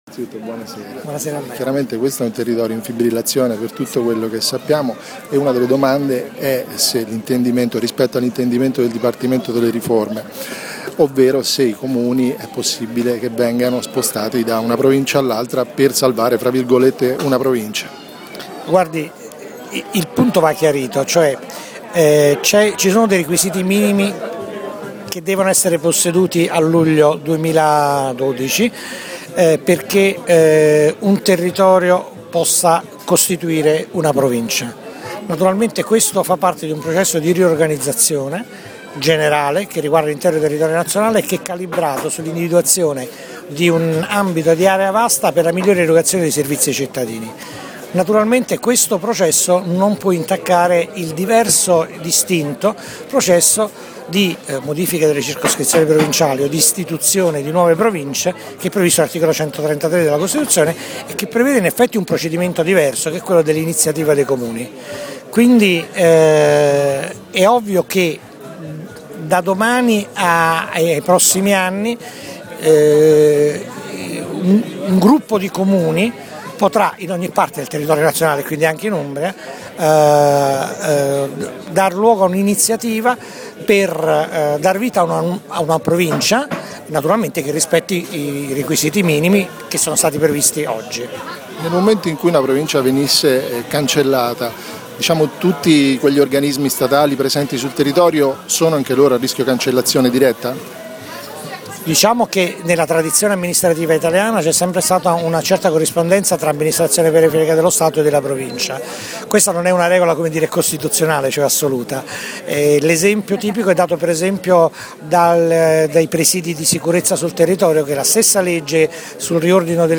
E proprio a Terni, alla festa del Pd, Patroni Griffi ha parlato di quanto stretta sia la strada del mantenimento della Provincia.
AUDIO – Intervista a Filippo Patroni Griffi